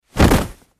Cloth; Parachute Opening, Various Cloth Flap And Movement.